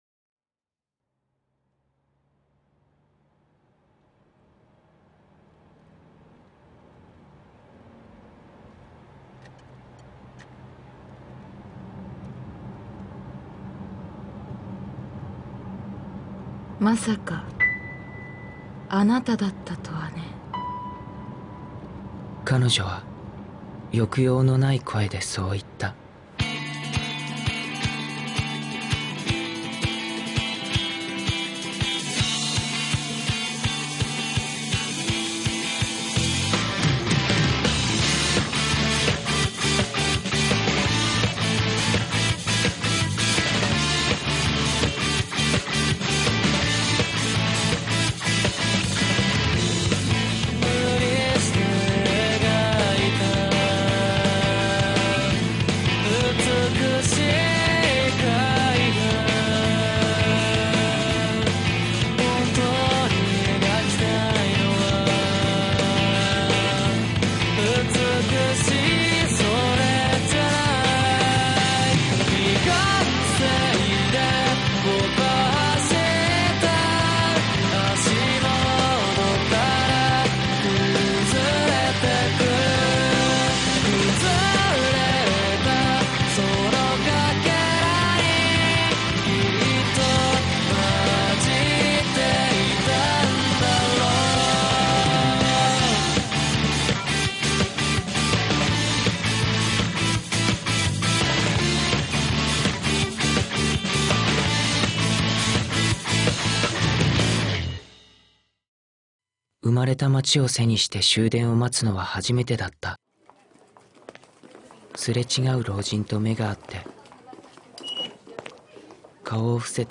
本編 – オーディオドラマ「シンメトリーな報復」 – Podcast